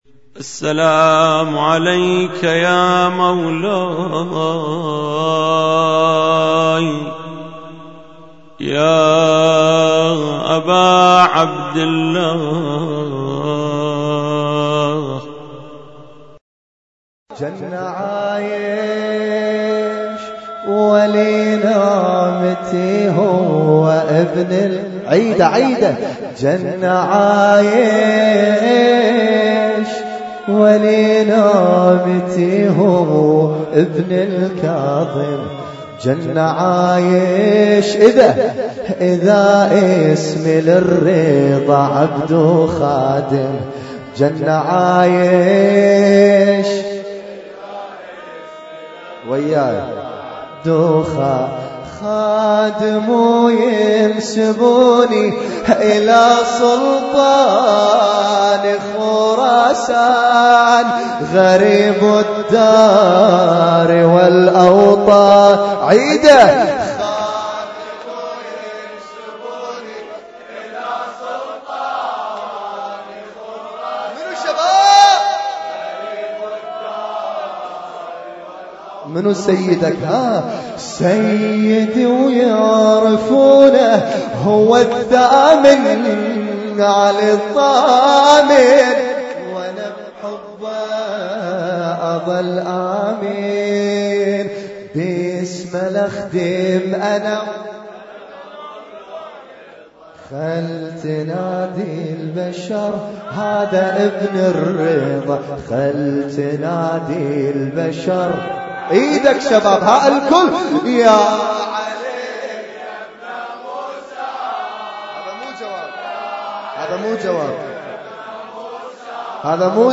Husainyt Alnoor Rumaithiya Kuwait
لطم شهادة الزهراء عليها السلام 1435